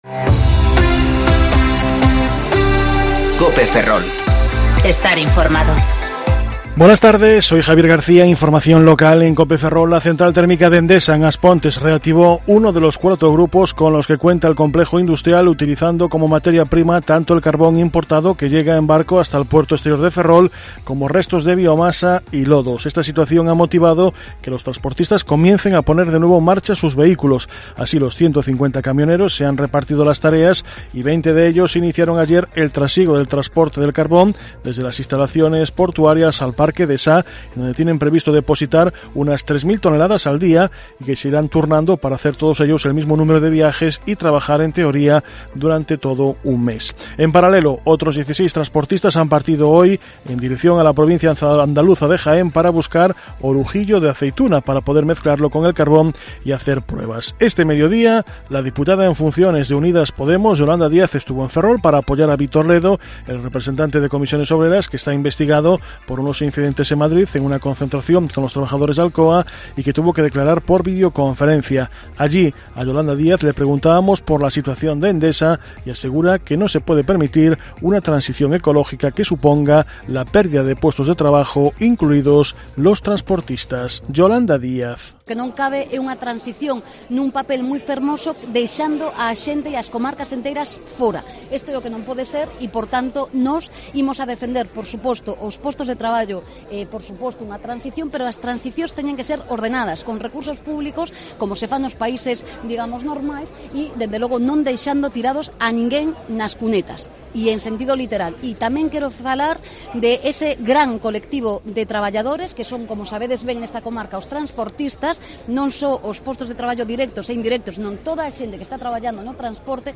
Informativo Medio Cope Ferrol 8/10/2019 (De 14.20 a 14.30 horas)